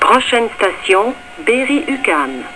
On peut maintenant mettre un visage à la voix !
berri-uqam.wav